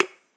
ui_button.ogg